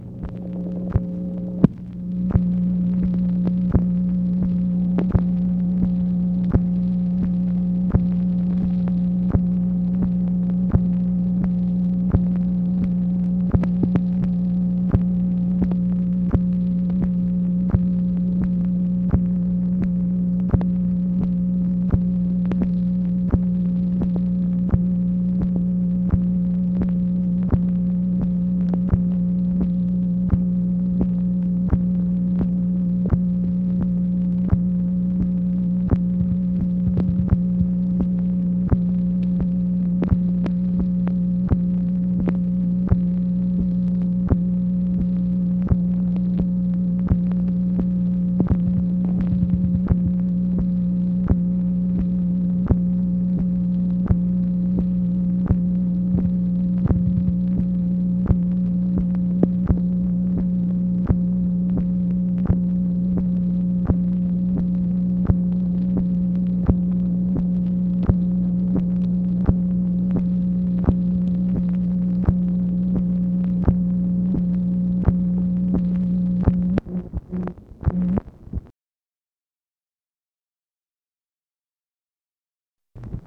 MACHINE NOISE, May 12, 1966
Secret White House Tapes | Lyndon B. Johnson Presidency